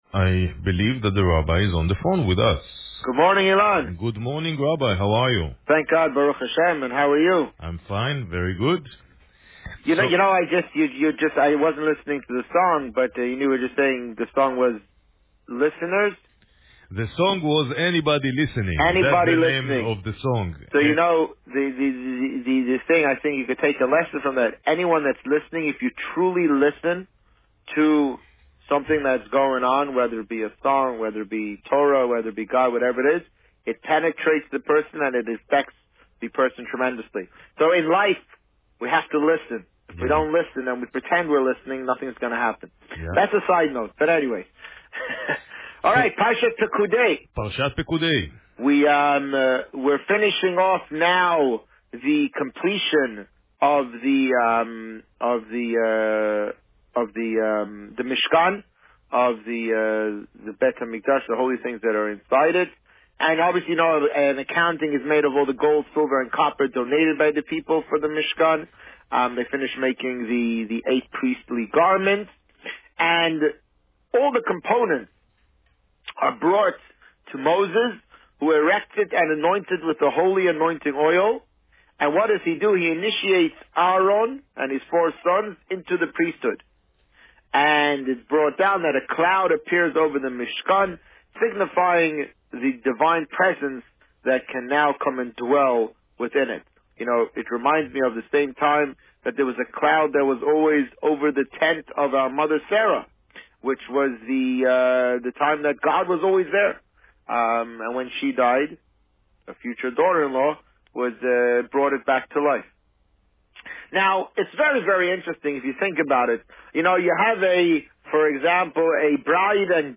The Rabbi on Radio
On March 10, 2016, the Rabbi spoke about Parsha Pekudei and the upcoming Purim festivities. Listen to the interview here.